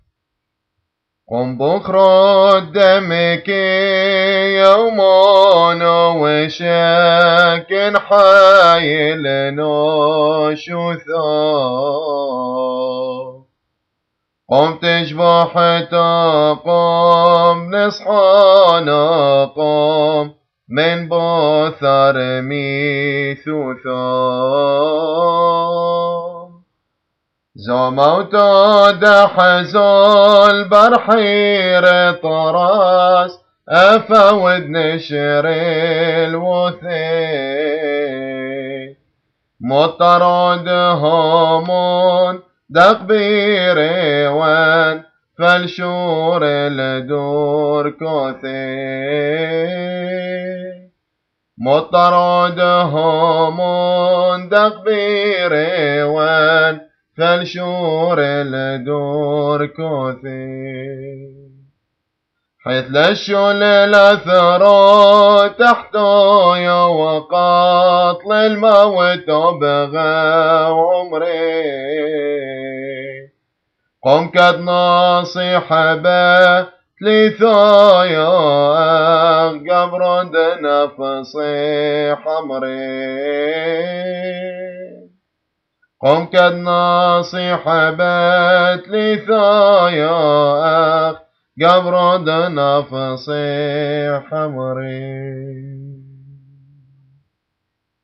Easter Feast Hymns
Qurobo Hymn